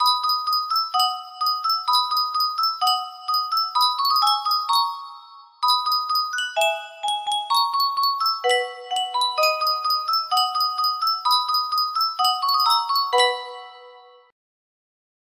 Yunsheng Music Box - Vivaldi The Four Seasons Autumn 4031 music box melody
Full range 60